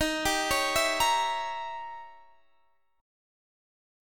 Eb7b9 Chord
Listen to Eb7b9 strummed